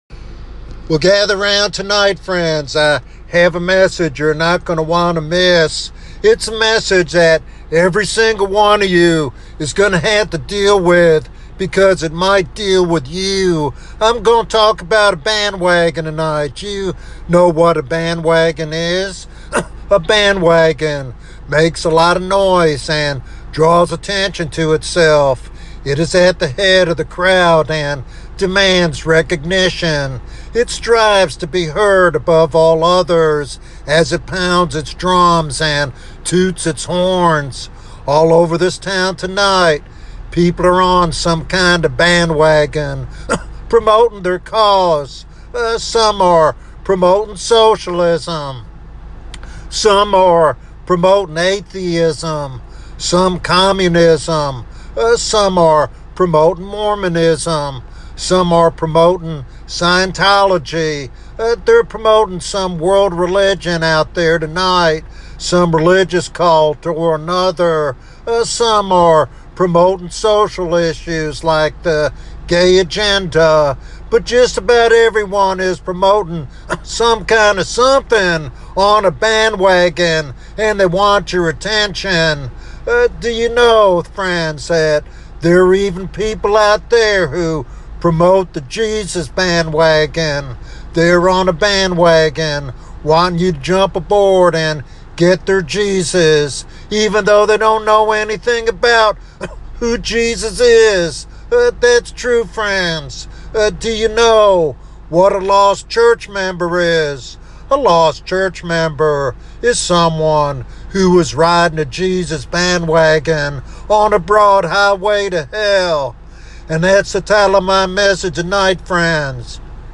In this powerful evangelistic sermon